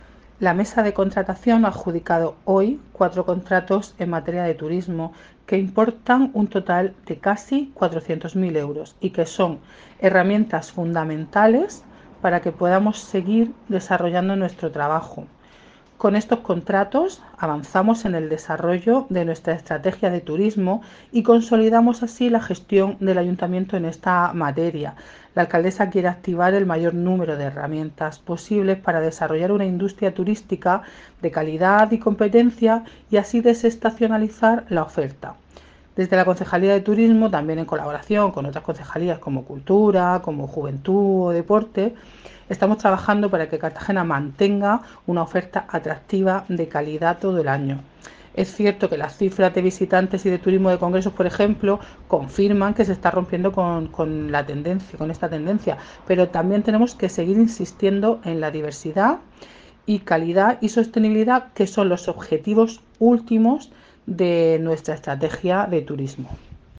Enlace a Declaraciones de la concejala de Turismo, Beatriz Sánchez